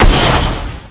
fire_no1.wav